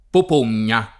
[ pop 1 n’n’a ]